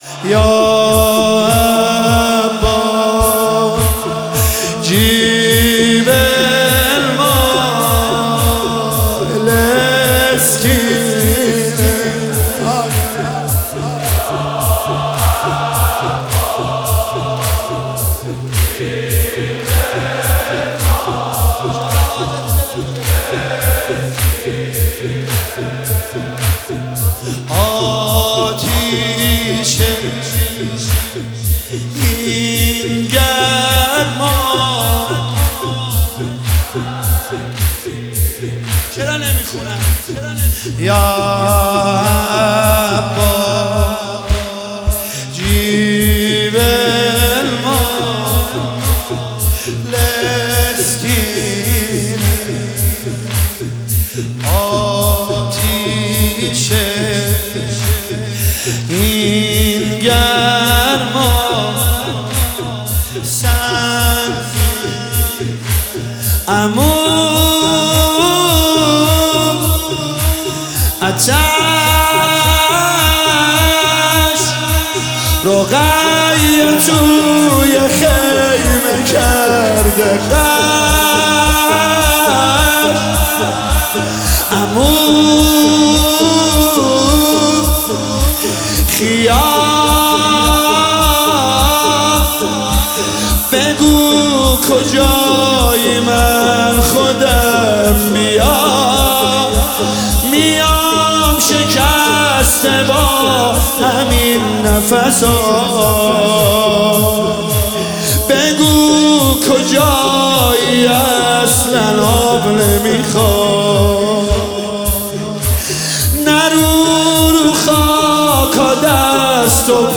دانلود مداحی زمینه
شب سوم فاطمیه 1403